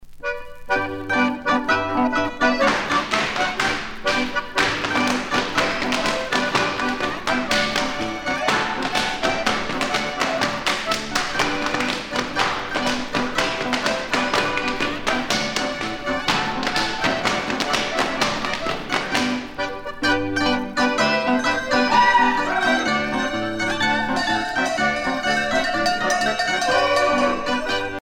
danse : schuhplattler (Bavière)
Pièce musicale éditée